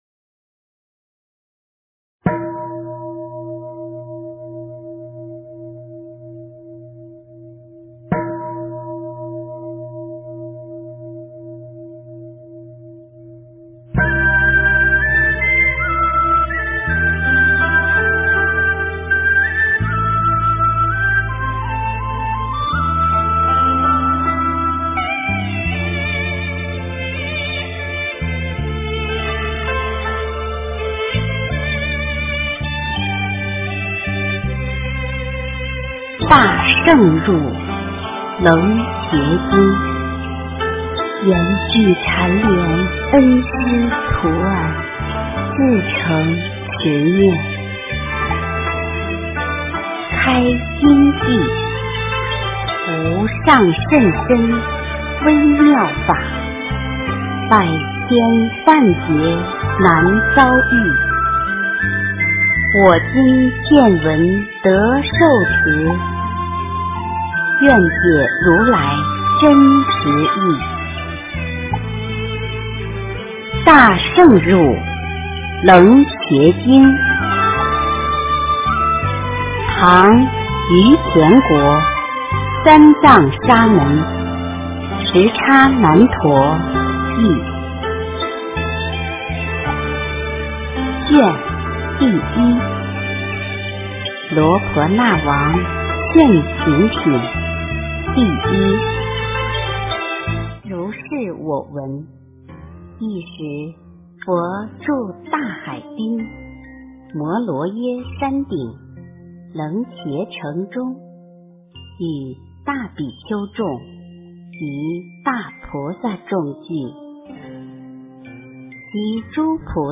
《楞伽经》第一卷上 - 诵经 - 云佛论坛